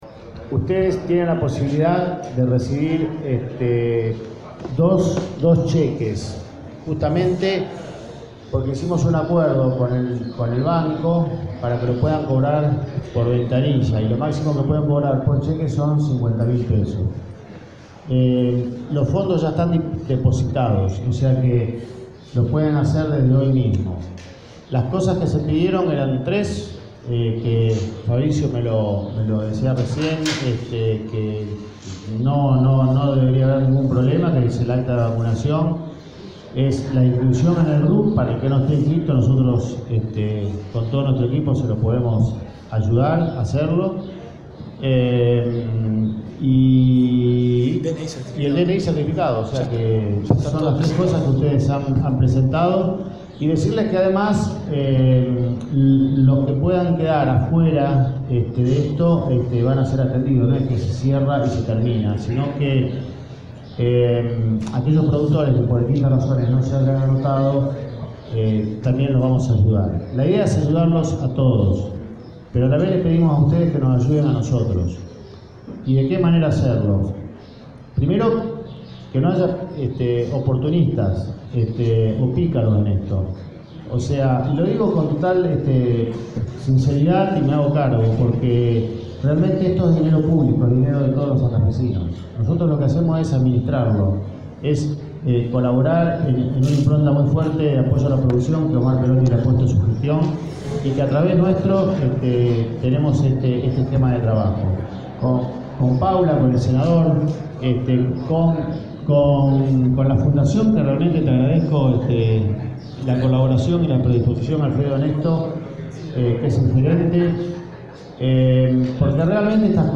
Ministro de la Producción, Daniel Costamagna